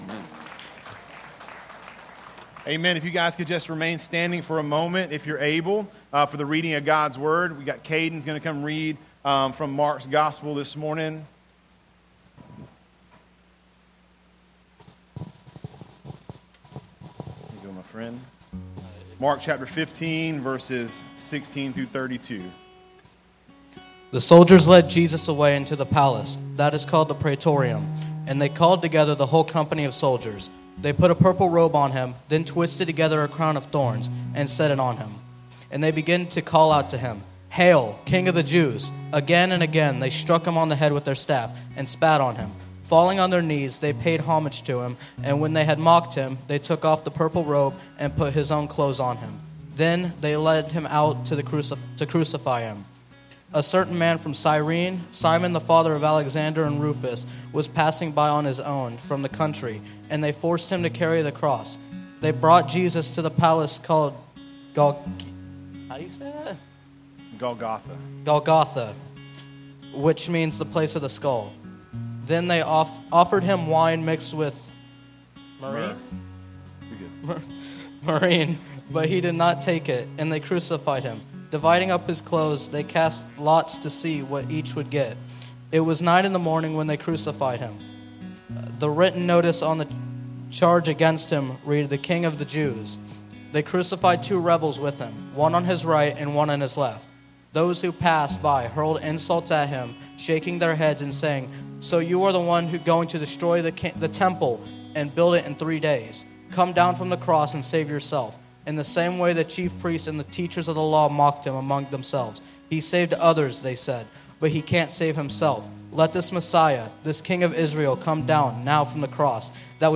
Sermons | Replicate Church